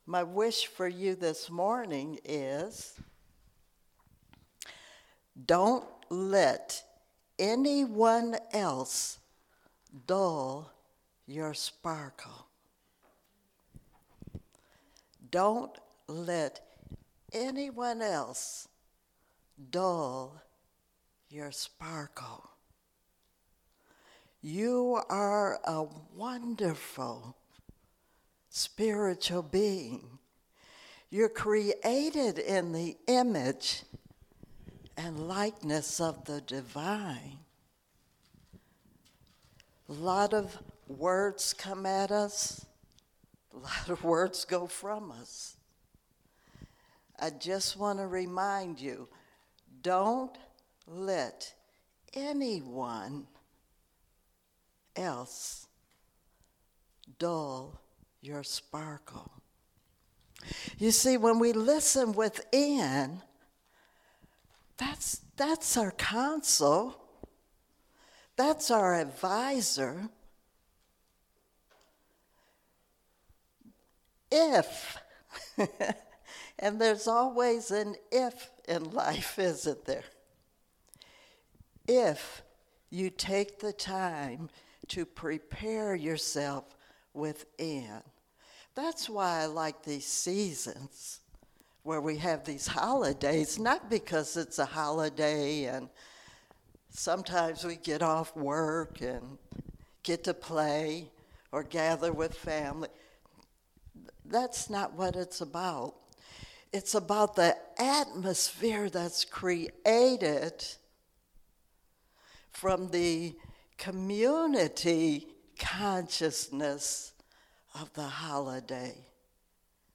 Series: Sermons 2022